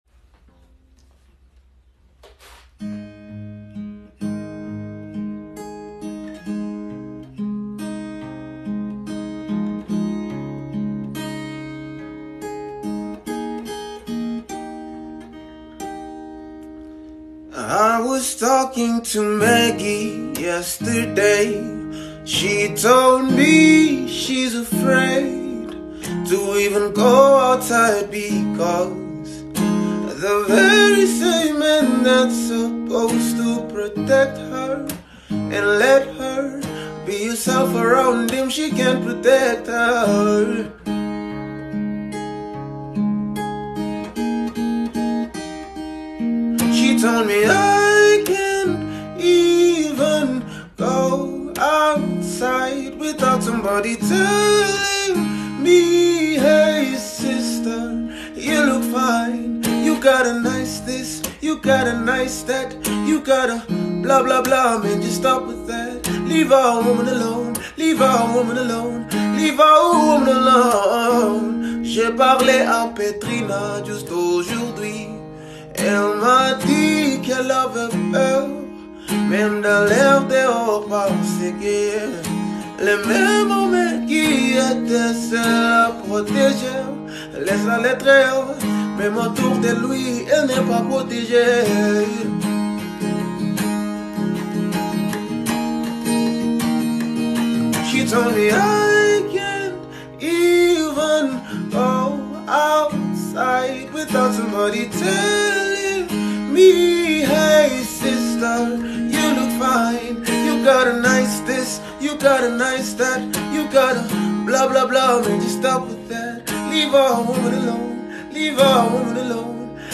live on the fresh Midmorning.